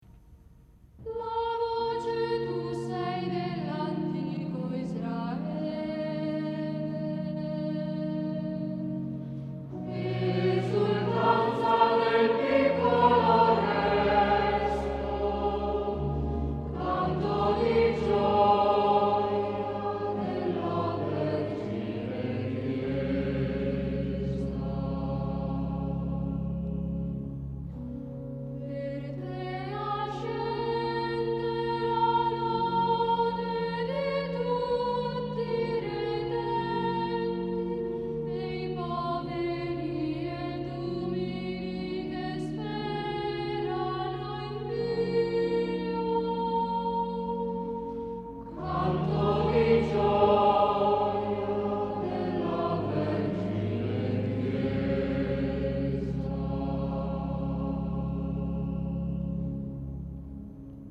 Secondo responsorio 1,428 Mb   Ascolto